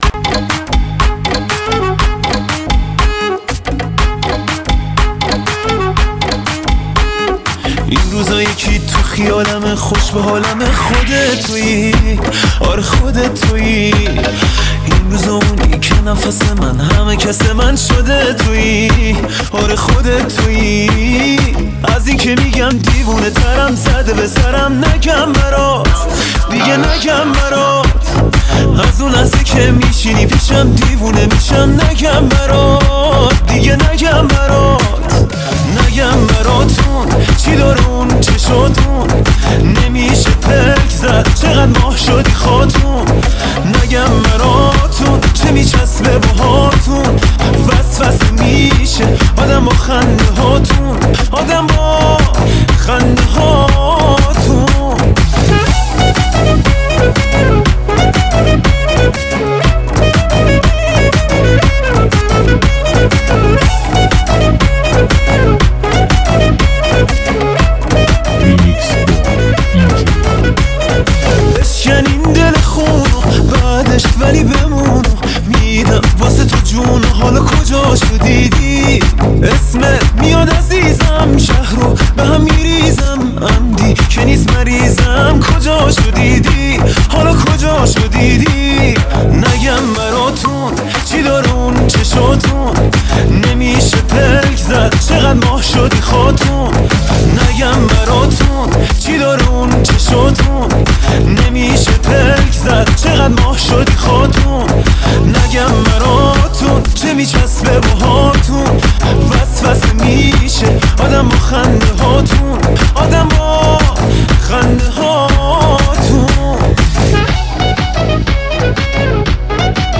موسیقی تریبال و پرانرژی برای لحظاتی پر از احساس و شادی.